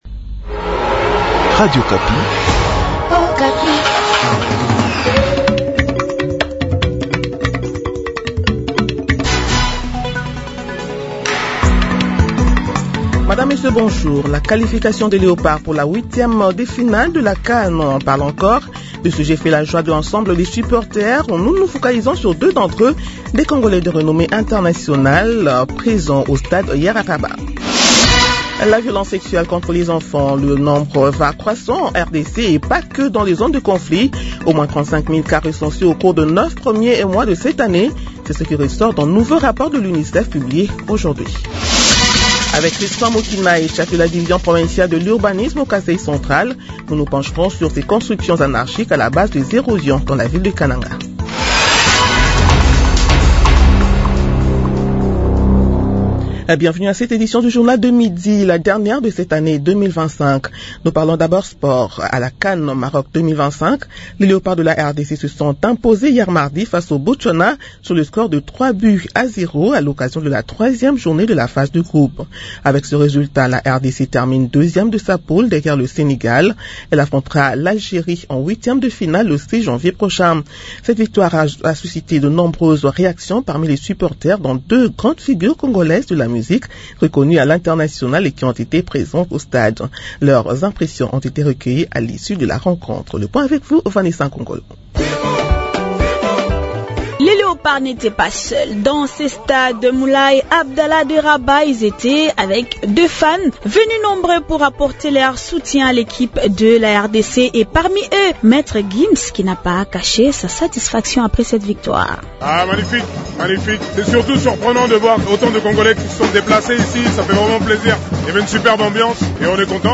Journal midi du mercredi 31 décembre 2025